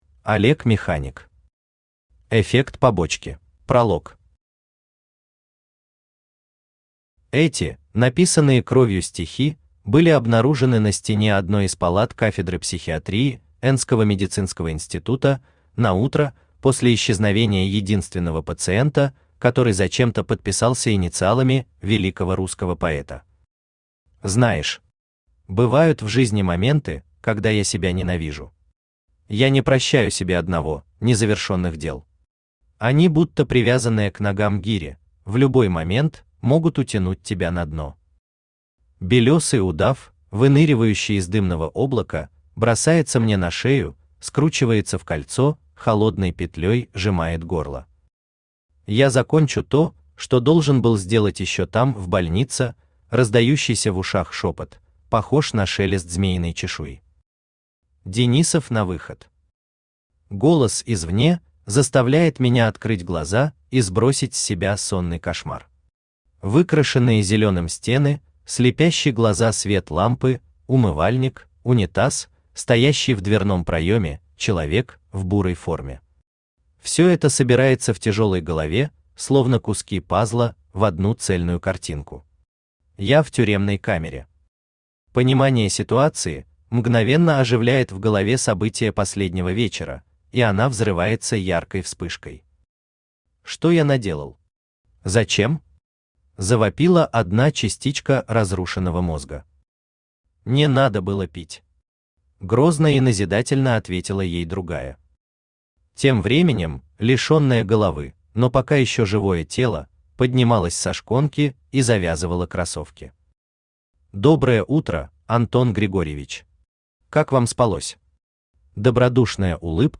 Aудиокнига Эффект побочки Автор Олег Механик Читает аудиокнигу Авточтец ЛитРес.